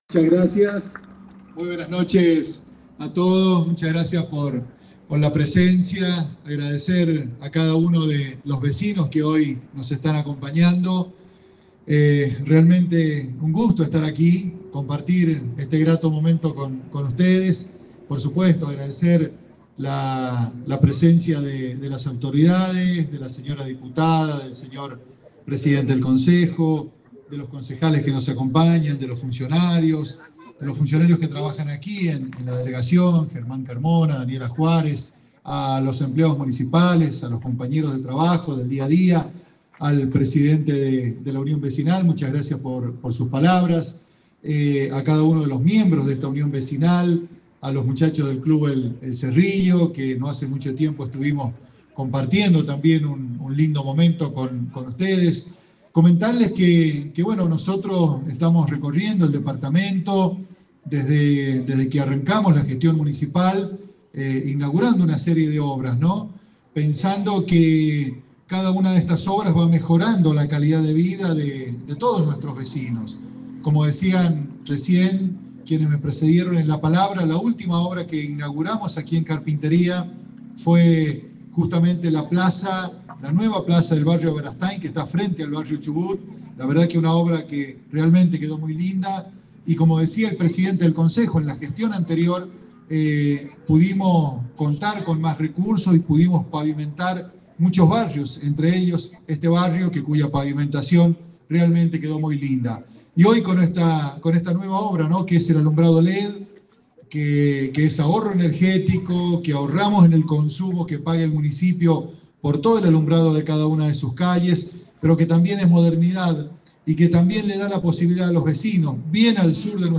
El intendente de Pocito, Fabian Aballay, inauguró la nueva iluminación LED en el Barrio Ruta 40 ubicado en la localidad de Carpintería, en la Zona Sur del departamento.
Palabras del Intendente, Fabian Aballay